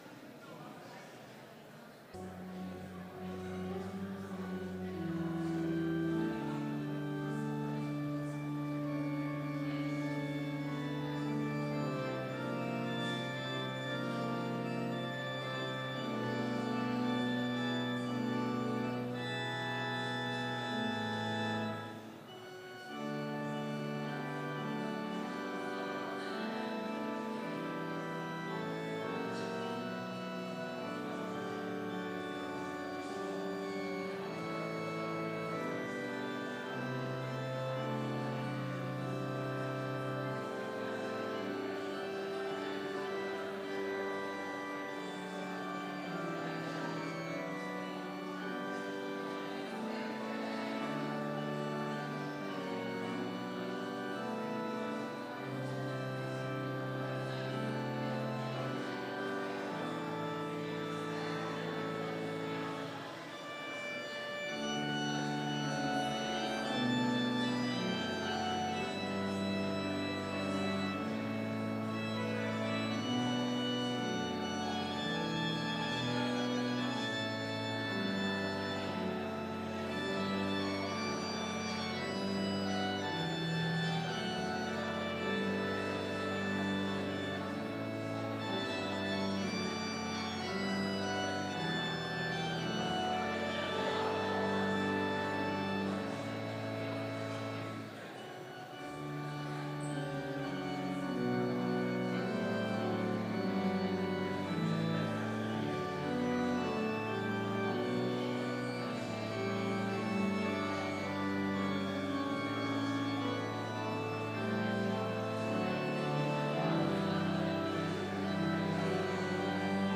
Complete service audio for Chapel - October 1, 2019